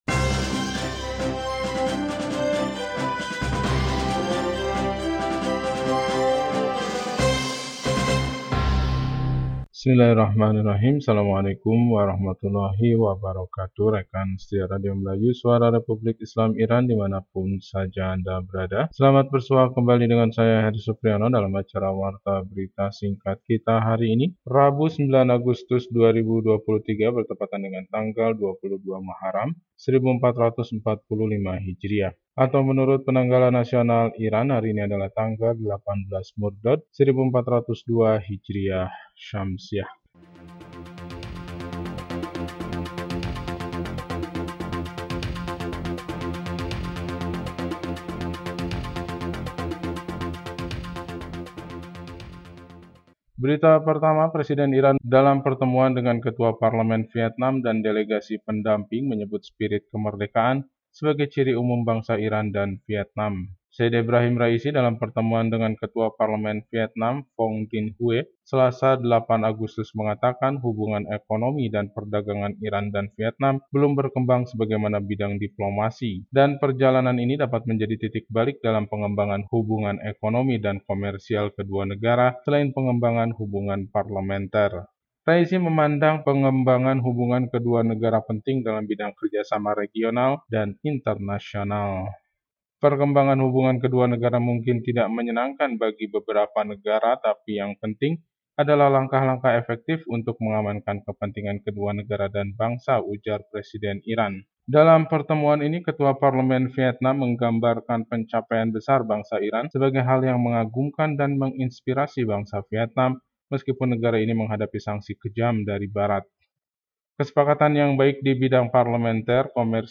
Warta Berita 9 Agustus 2023